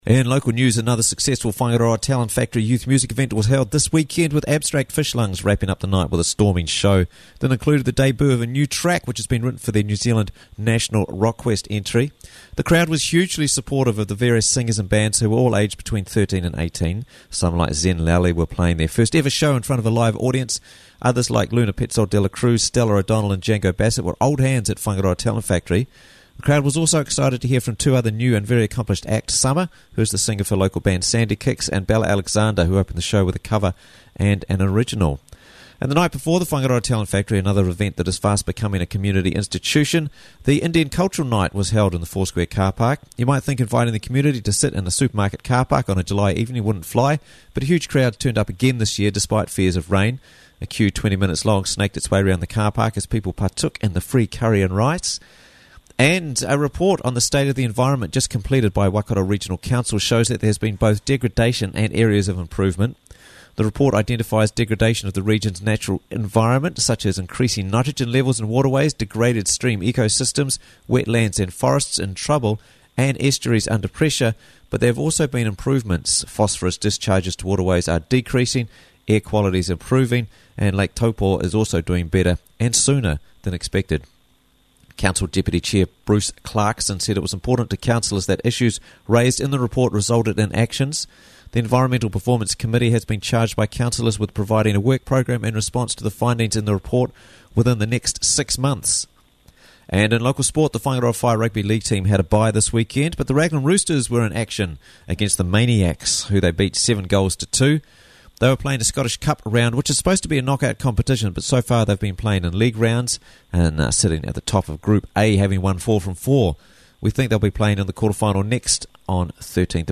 Quickly and easily listen to Raglan News Bulletin for free!